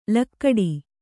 ♪ lakkaḍi